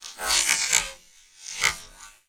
ALIEN_Communication_02_mono.wav